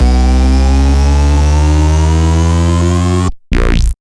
BASS1.wav